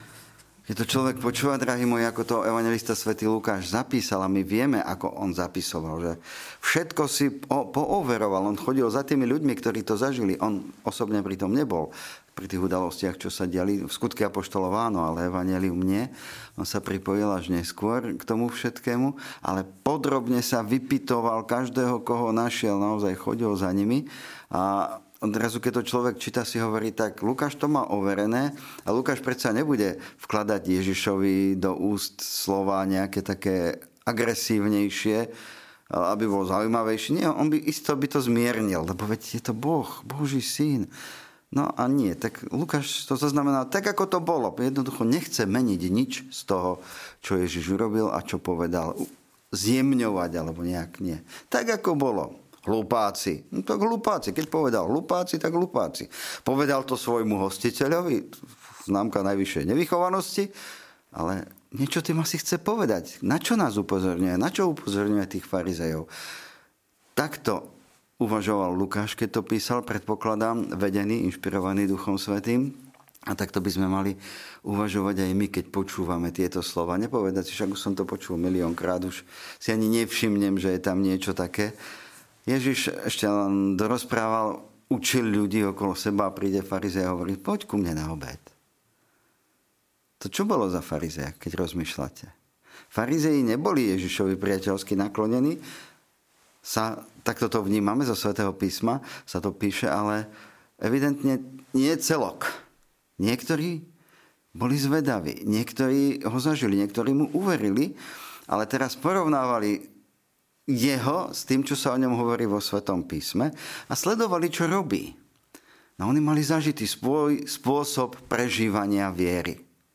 Kázne